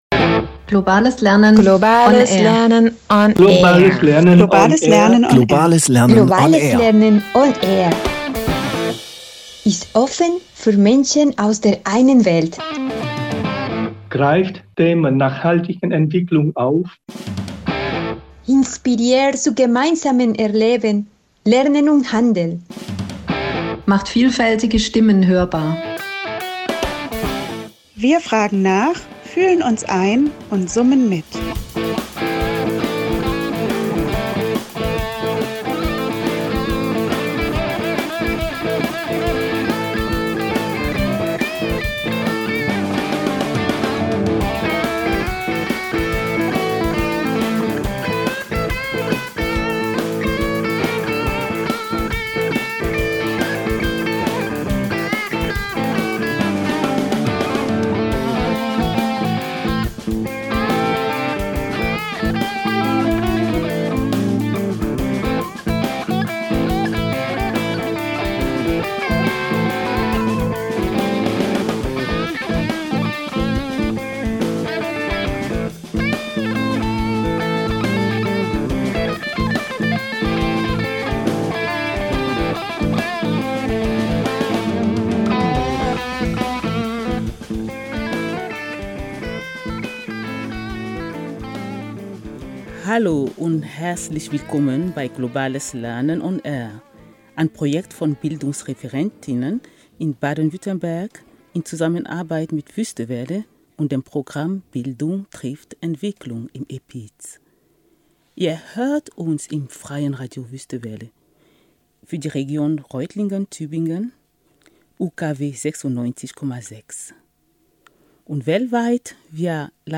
Ein spannendes Gespräch über Liebe, Spiritualität, Kolonialismus und Musik.